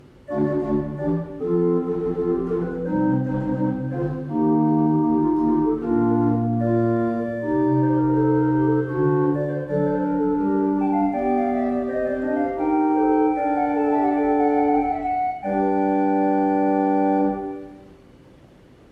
Rohrflöte 8'
Das Instrument befindet sich in gutem Zustand, ist recht mild intoniert und daher für kleine Kirchenräume oder als Haus- und Übeorgel gut verwendbar.